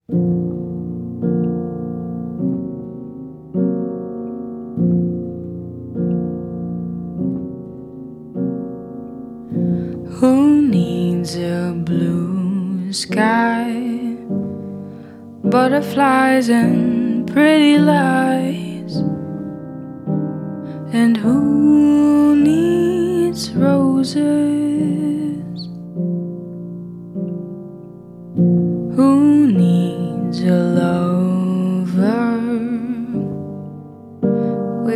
Жанр: Поп
# Jazz